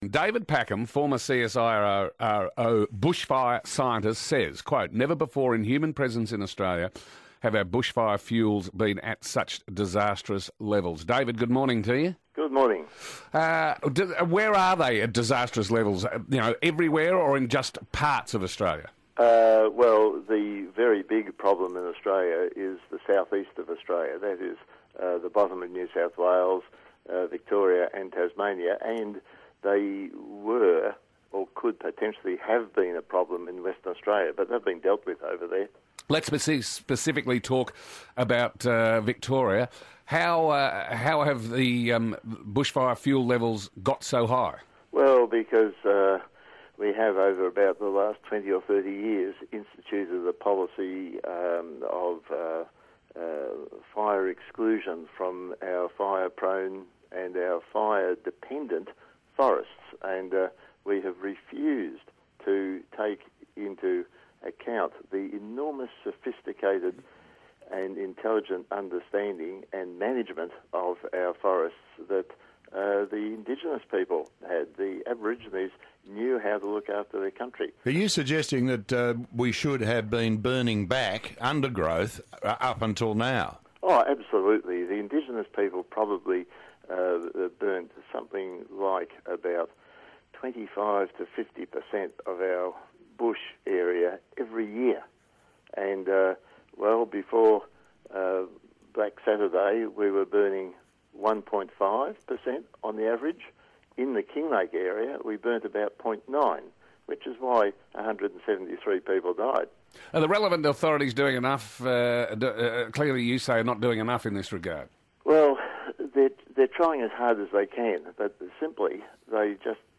Victoria could be heading for another deadly bushfire disaster, an expert has told 3AW Breakfast.